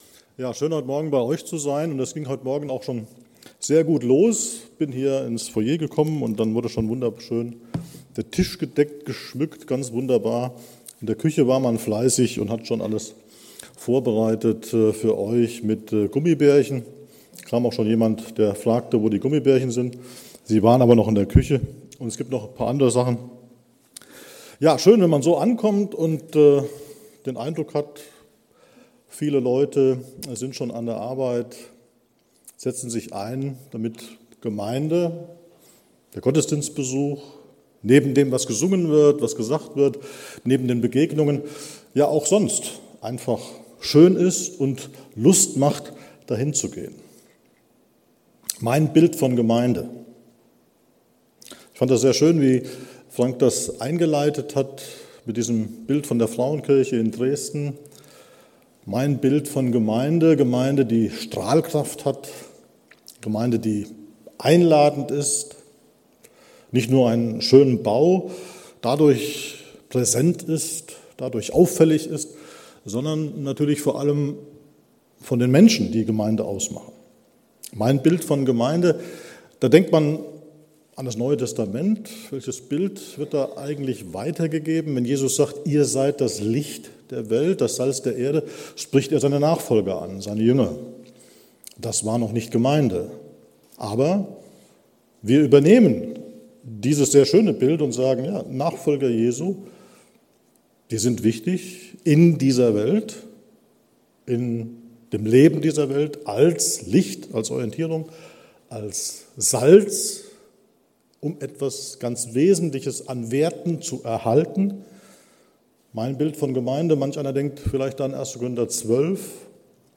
Serie: Gastprediger